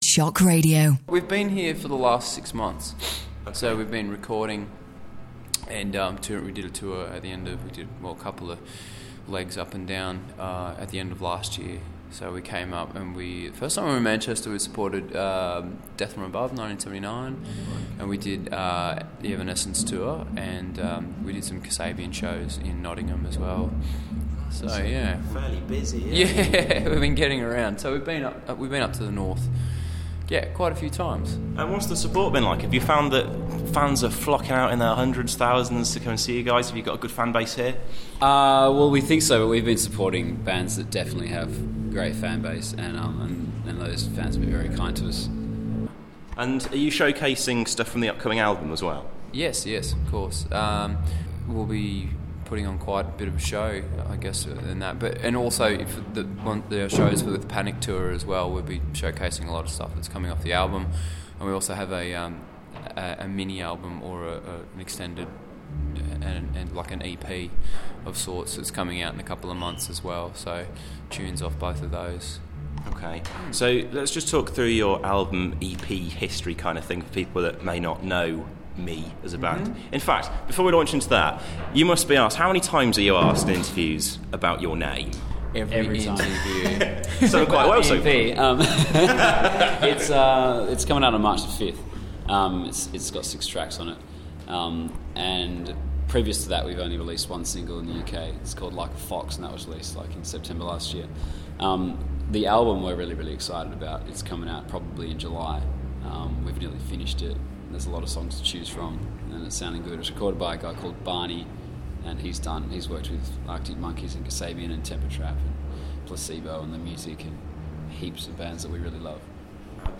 An interview with Australians [ME] at Manchester Apollo, ahead of their gig with Panic! At The Disco